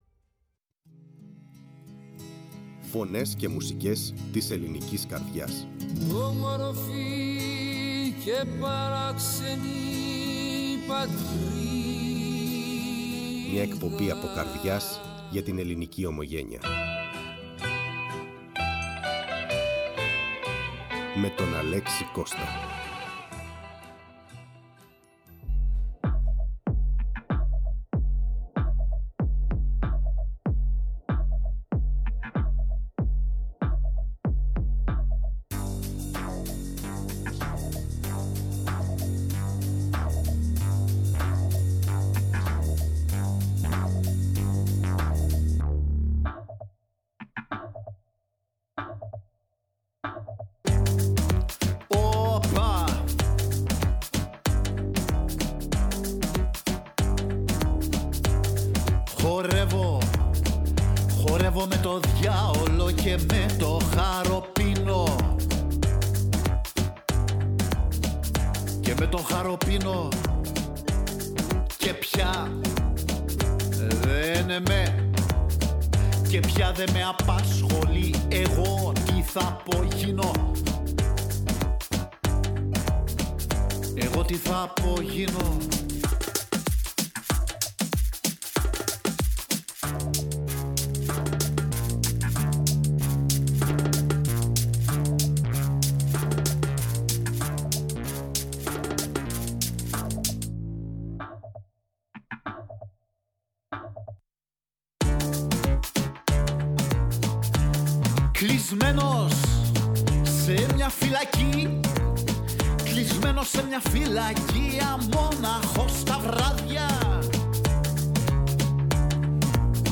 Κρητικη Λυρα